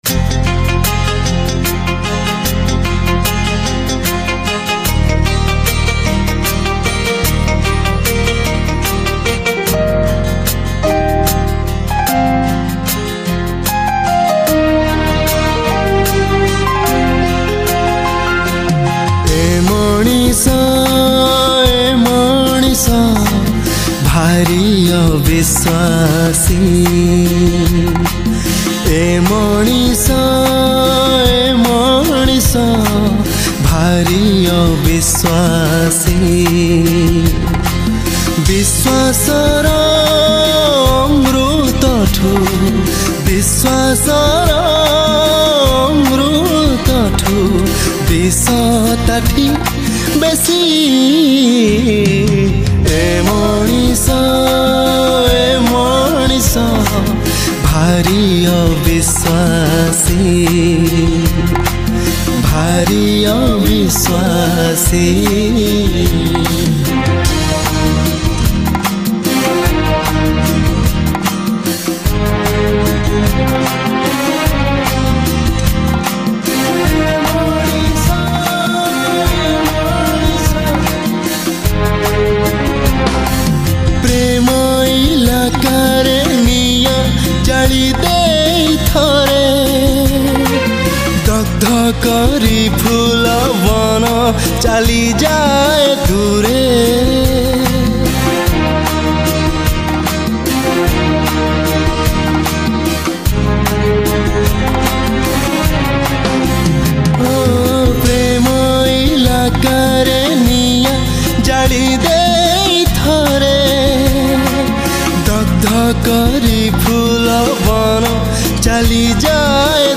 New Odia Sad Song